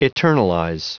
Prononciation du mot eternalize en anglais (fichier audio)
Prononciation du mot : eternalize